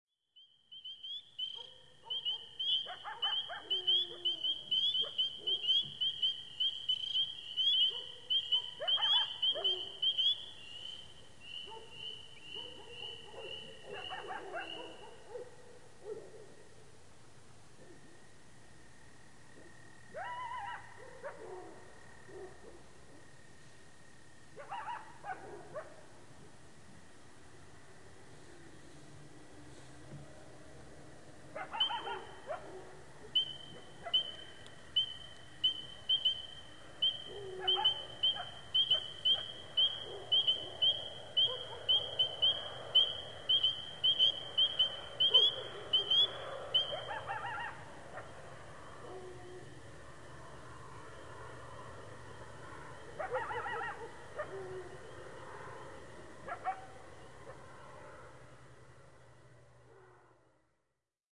Late Night Peeper Sand Coyotes March 302012 Bouton sonore